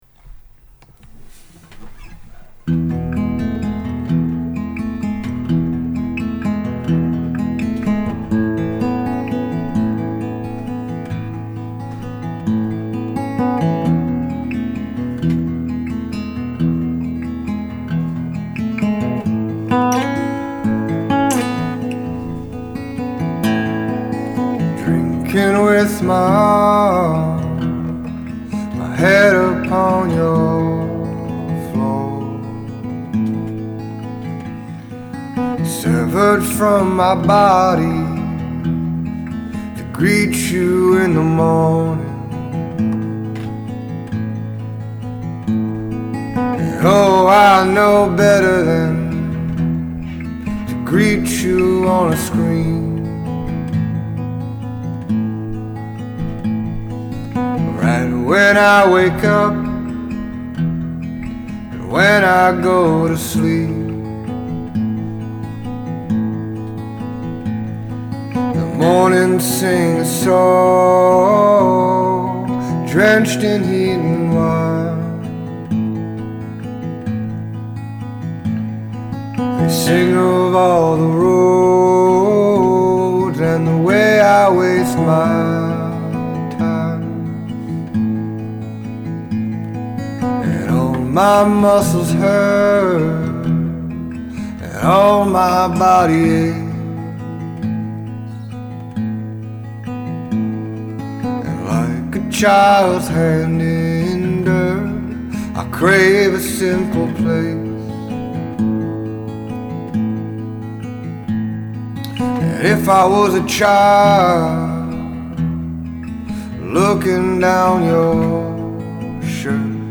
The album can be considered “folk”